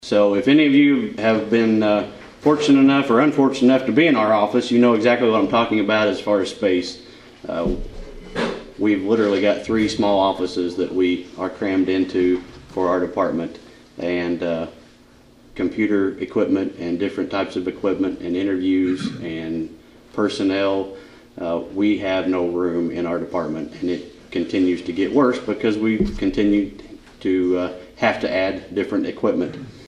Carter Gives Update on new Carmi PD Building at Kiwanis Meeting
Carter gave an update on the new Carmi Police Department, the old Leggett’s Market building, at the Thursday Kiwanis meeting.